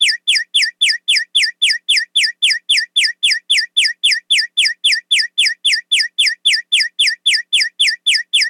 Efecto semáforo cuando está verde para los peatones
Señales: Tráfico
Sonidos: Ciudad